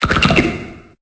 Cri de Nodulithe dans Pokémon Épée et Bouclier.